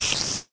minecraft / sounds / mob / spider / say3.ogg